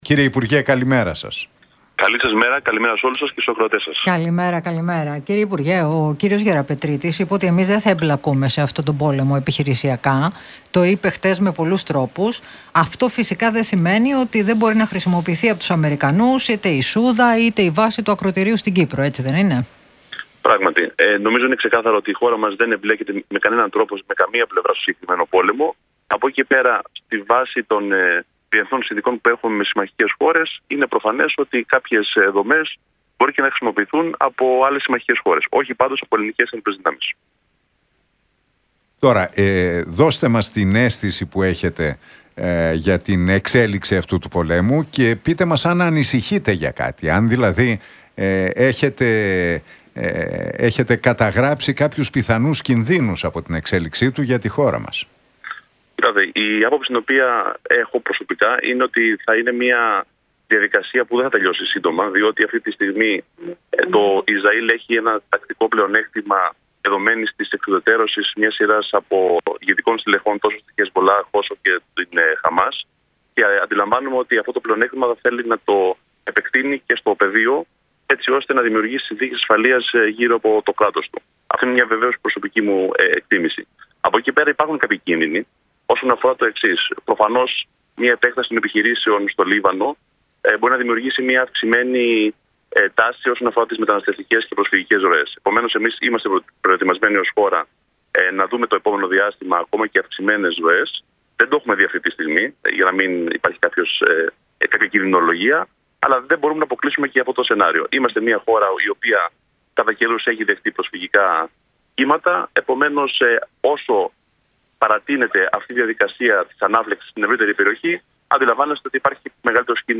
Για τα πολεμικά μέτωπα στην Μέση Ανατολή, τις ελληνοτουρκικές σχέσεις, τις στρατιωτικές σχολές και τις αλλαγές στην στρατιωτική θητεία μίλησε ο υφυπουργός Εθνικής Άμυνας, Γιάννης Κεφαλογιάννης στους Νίκο Χατζηνικολάου
από τη συχνότητα του Realfm 97,8